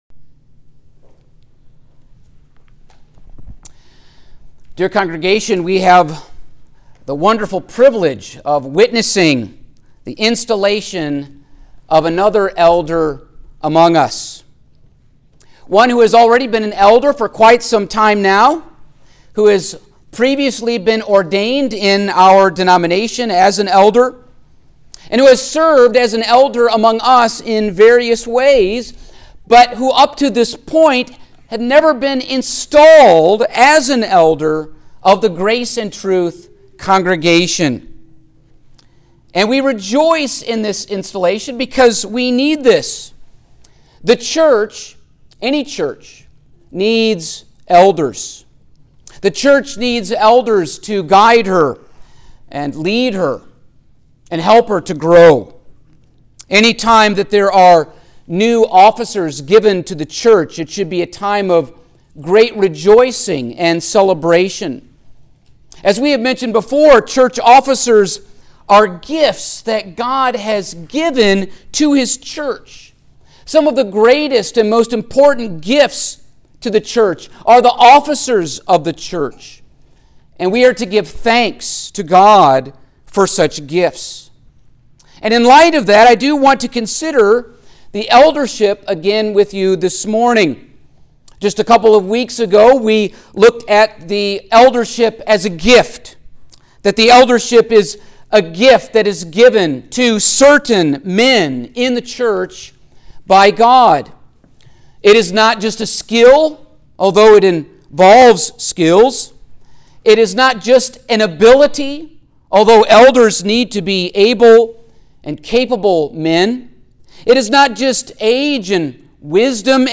Passage: Acts 20:17-38, 1 Peter 5:1-4 Service Type: Sunday Morning